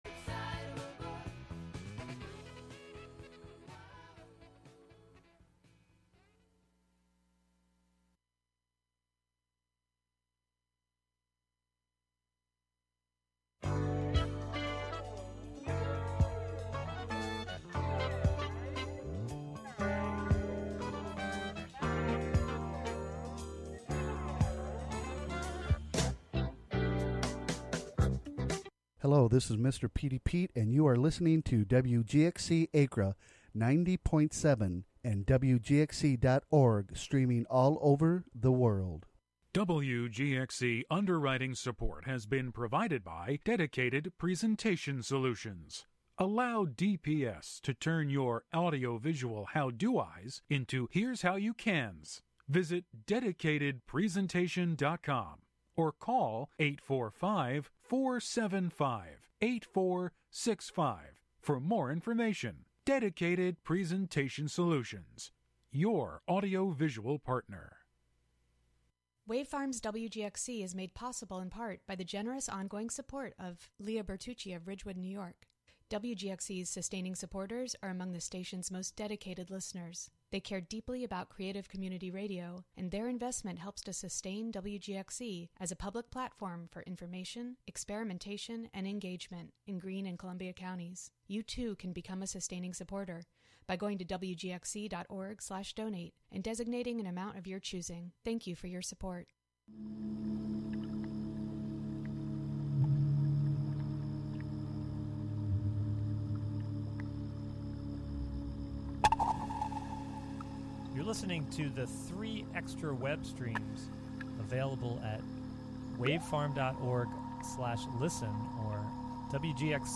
Monthly excursions into music, soundscape, audio document and spoken word, inspired by the wide world of performance. Live from Ulster County.